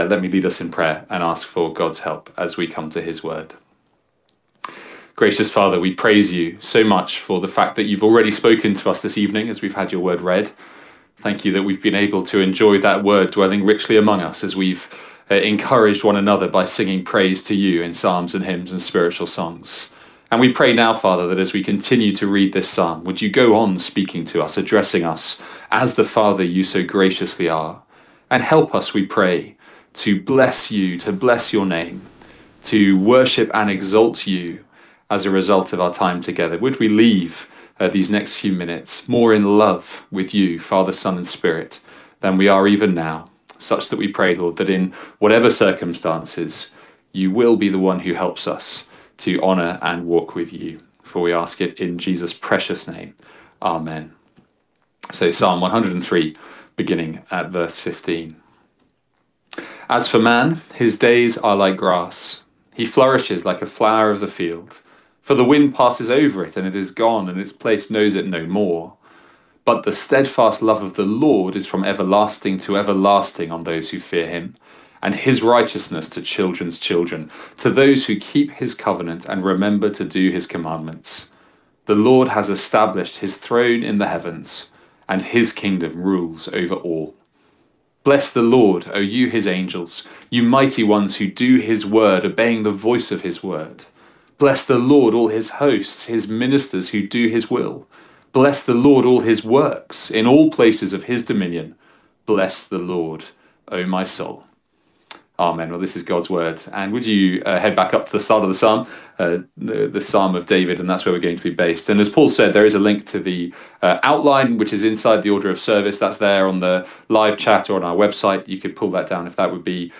A one off sermon on Psalm 103.